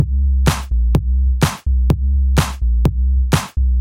更深层次的电子鼓与副鼓
Tag: 126 bpm Electro Loops Drum Loops 656.52 KB wav Key : C